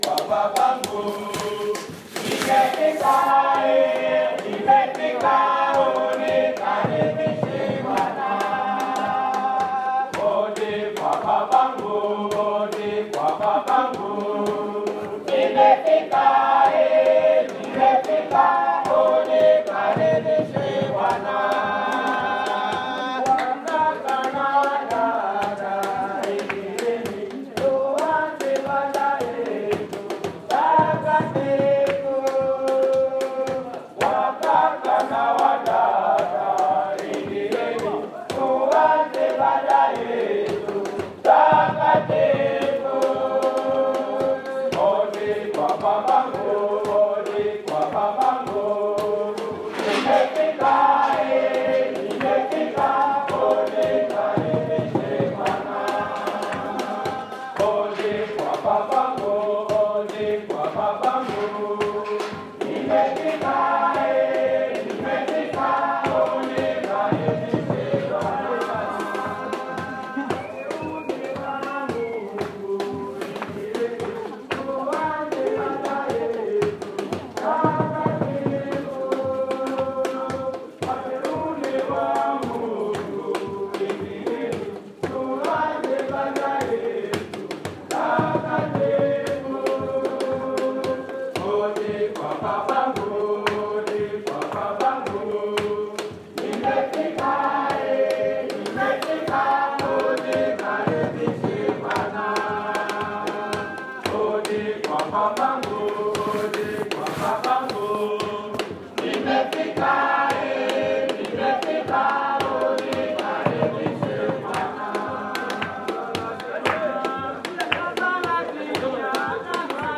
Malgré la chorale qui donne de la voix, tente de réchauffer l’atmosphère, ces hommes regardent dans le vide.
chant-prison-de-kabare.m4a